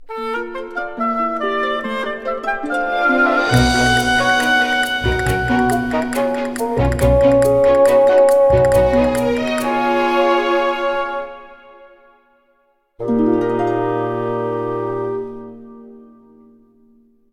Game rip
applied fade-out to last two seconds when needed
Fair use music sample